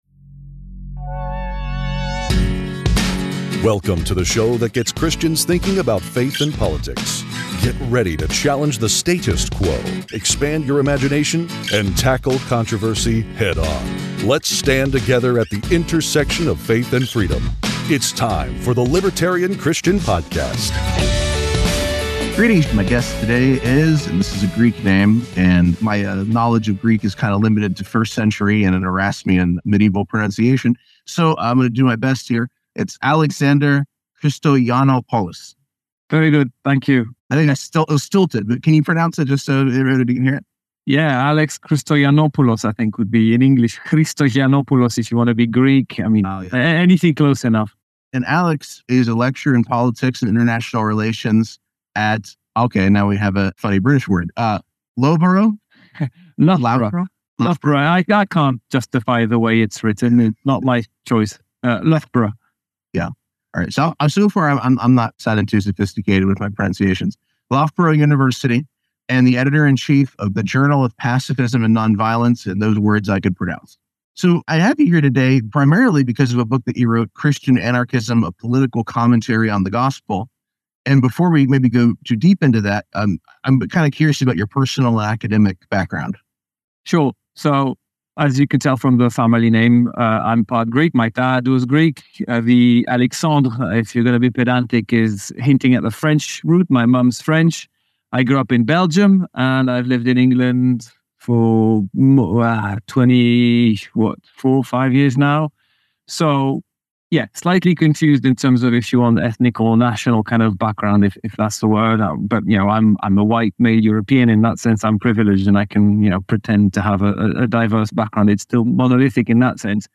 This was a great conversation that we are excited to share with you all.